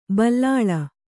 ♪ ballāḷa